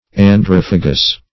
Androphagous \An*droph"a*gous\, a.
androphagous.mp3